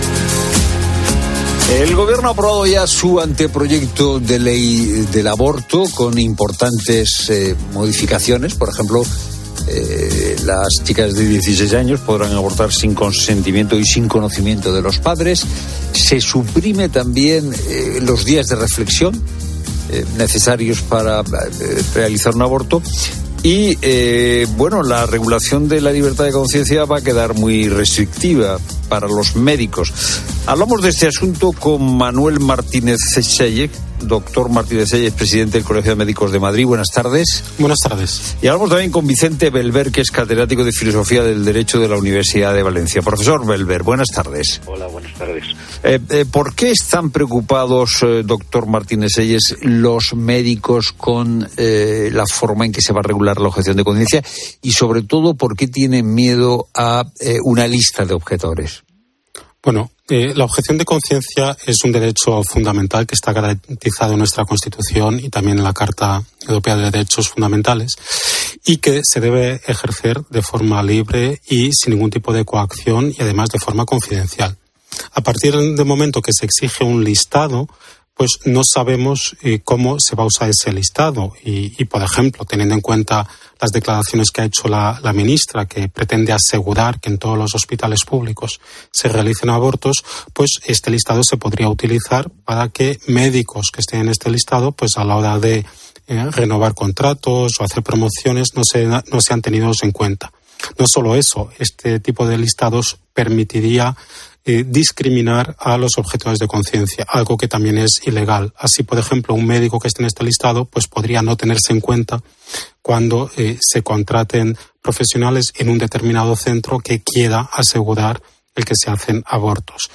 Puedes escuchar ya la entrevista completa aquí, en La Tarde de COPE